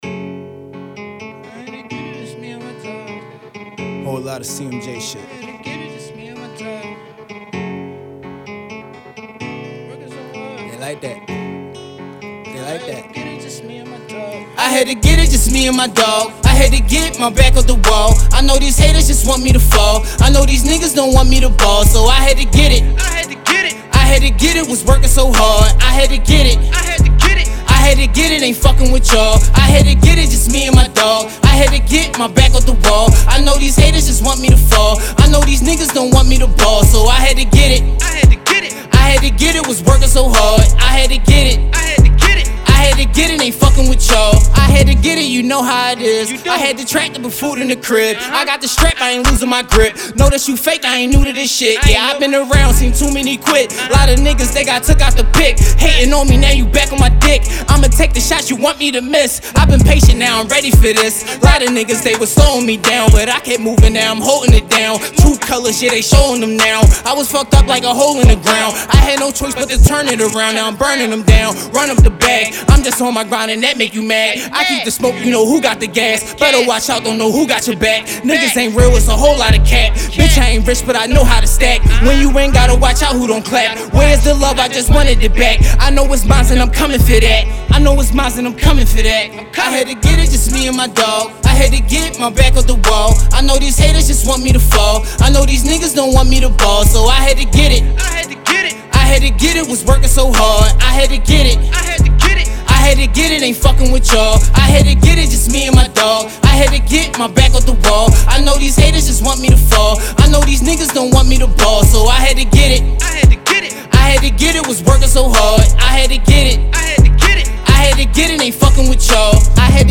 Rap
A track full of energy and beats!